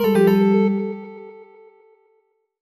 jingle_chime_20_negative.wav